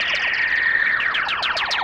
RI_RhythNoise_130-02.wav